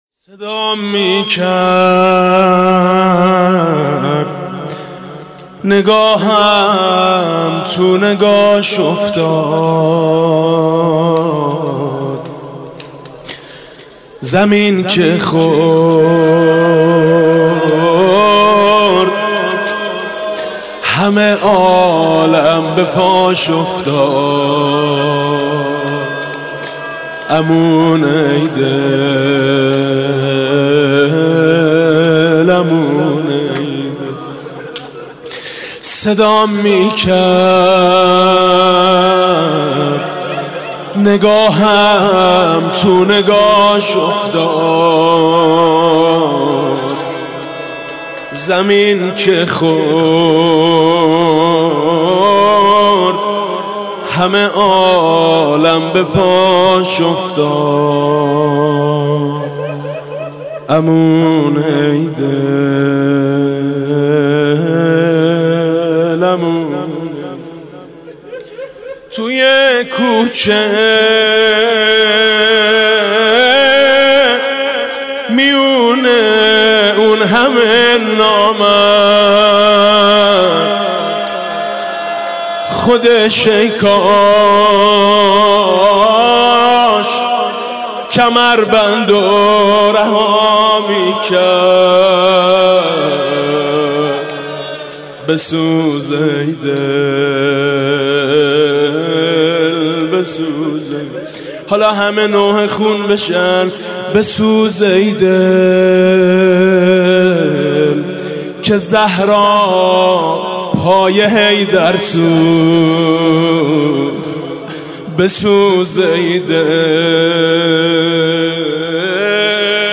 28 بهمن 96 - مسجد علی بن موسی الرضا - زمزمه - علی برگرد